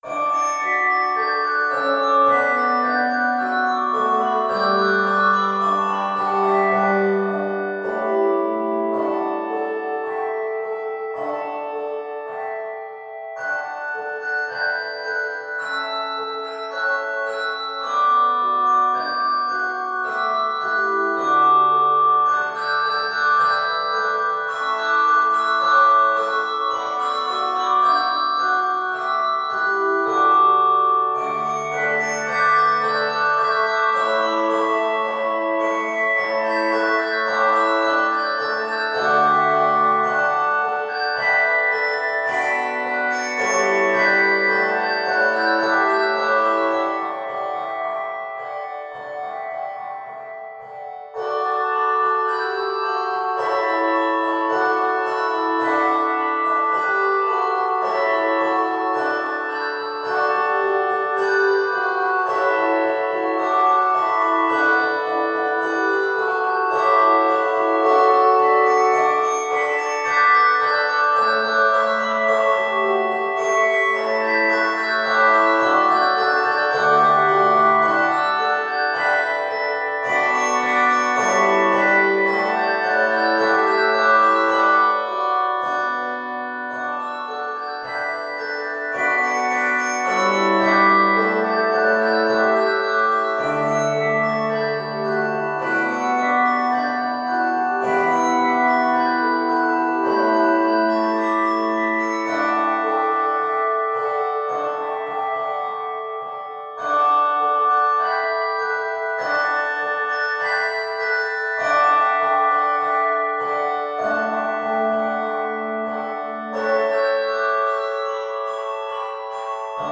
Key of D Major.